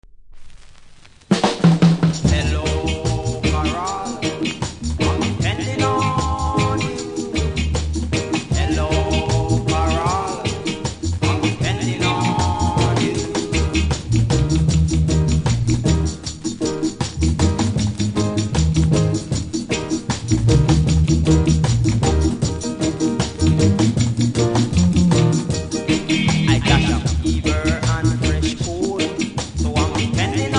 多少うすキズありますが音は良好なので試聴で確認下さい。